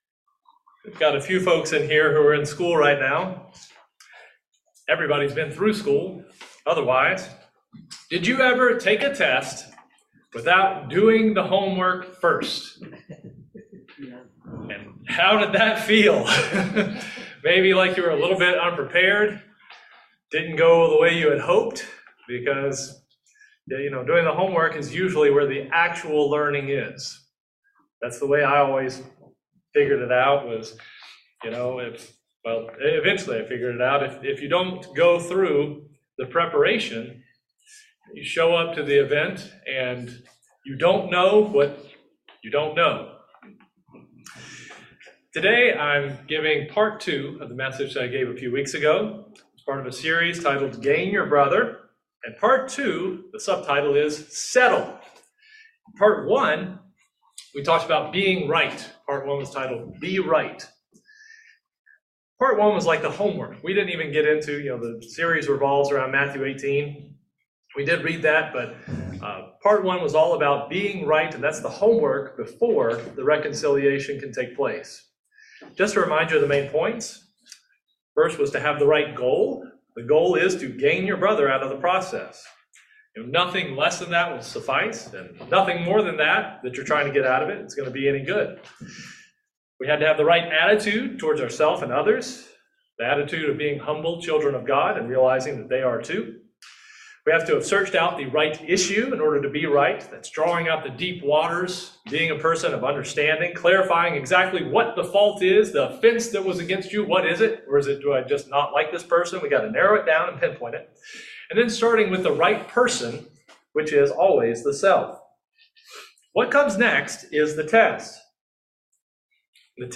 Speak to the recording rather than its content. Given in Central Georgia